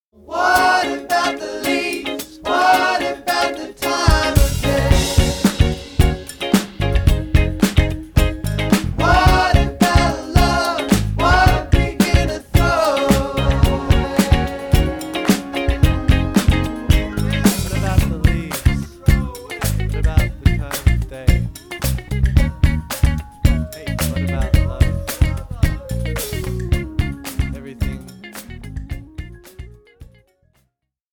Positive, fun, useable for all